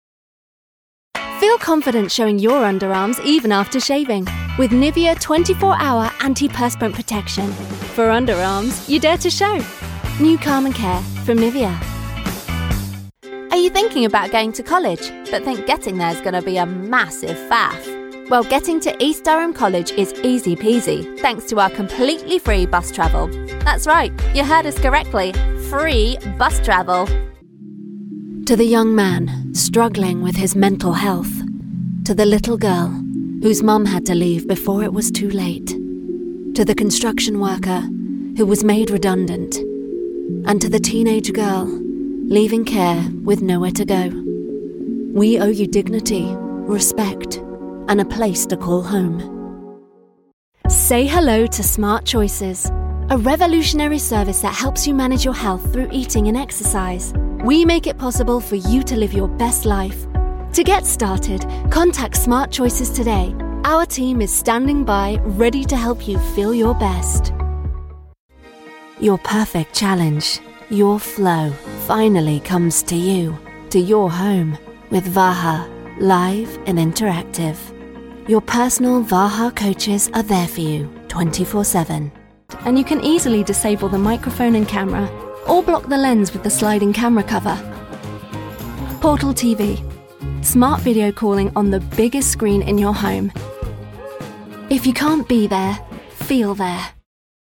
Commercial Showreel
Female
Neutral British
Estuary English
Bright
Friendly
Warm
Youthful